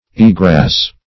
eagrass - definition of eagrass - synonyms, pronunciation, spelling from Free Dictionary Search Result for " eagrass" : The Collaborative International Dictionary of English v.0.48: Eagrass \Ea"grass\, n. See Eddish .